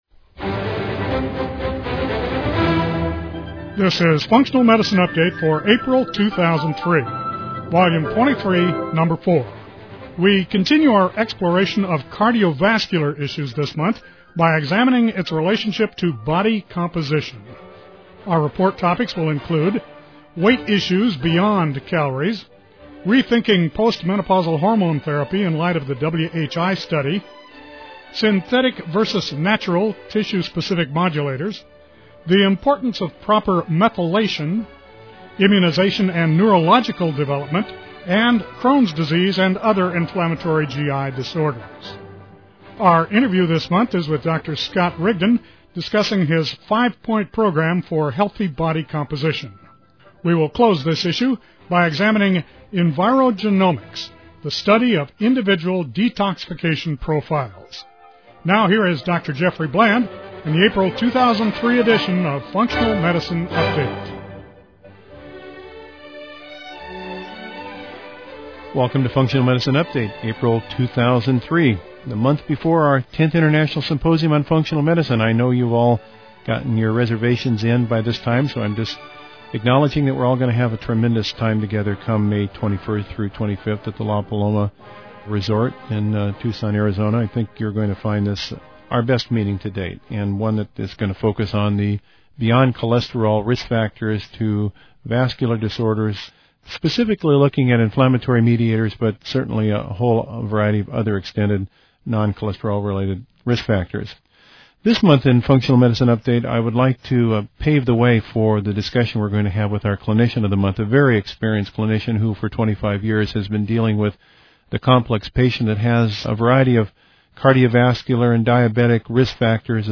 This month in FMU , I would like to pave the way for my discussion with our Clinician of the Month.